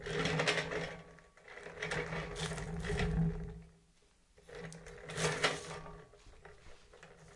机械声音" 16
描述：用轮子滚动一个大物体
Tag: 动感 锁存器 机器 机械 呼呼